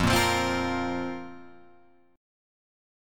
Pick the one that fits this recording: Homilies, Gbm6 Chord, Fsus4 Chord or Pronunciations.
Fsus4 Chord